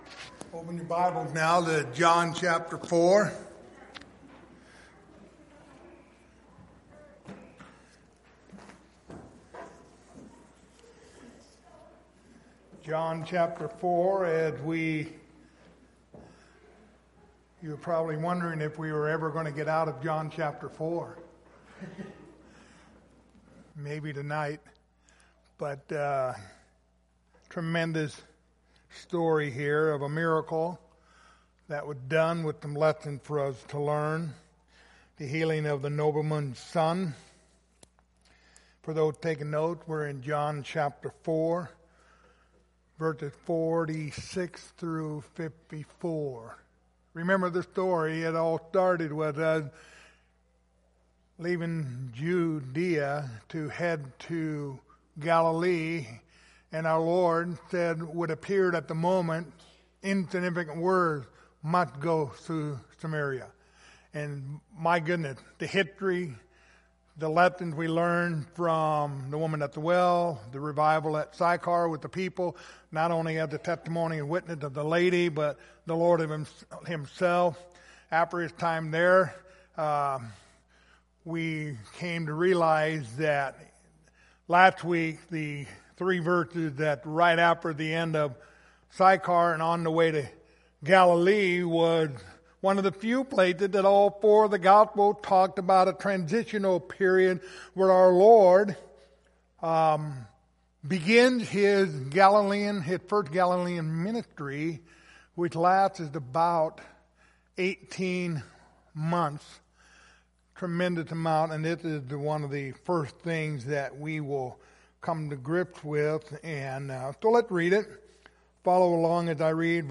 Passage: John 4:46-54 Service Type: Wednesday Evening